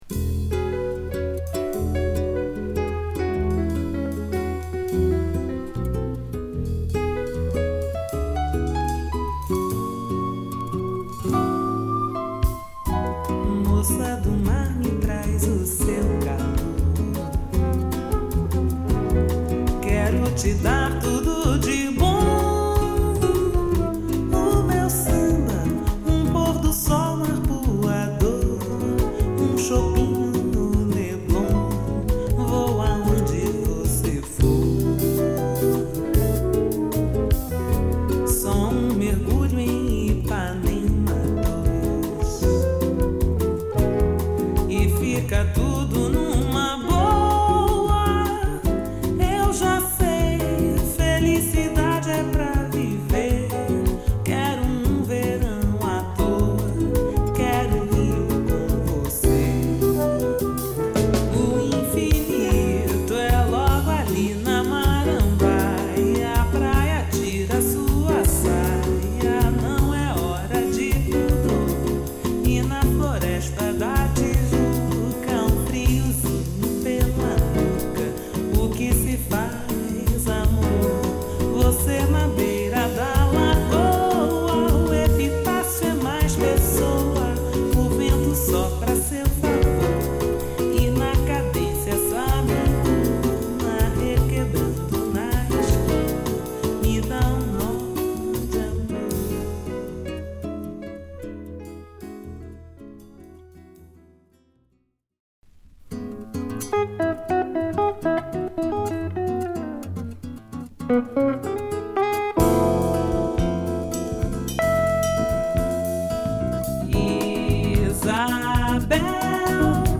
Home > Bossa Nova